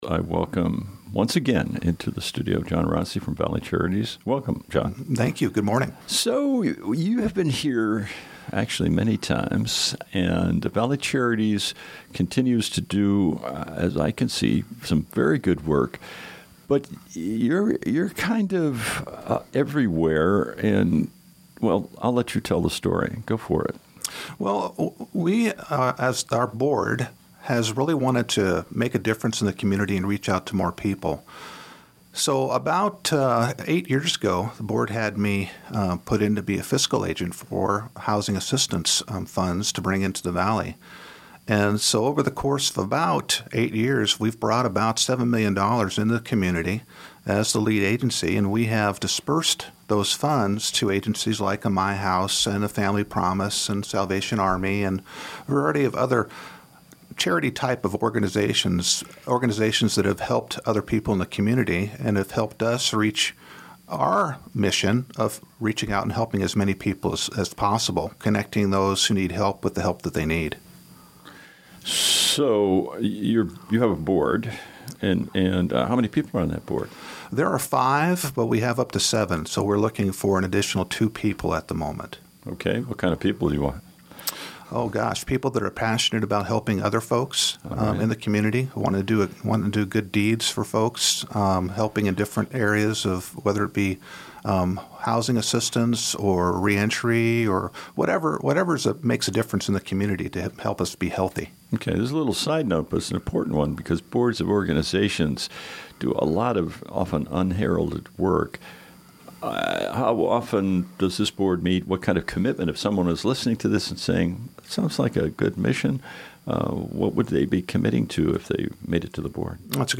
Enjoy the recording: ValleyCharities2018-12-19 Pick, Click and Give Interview: Clear Creek Cat Rescue 2018-12-21 →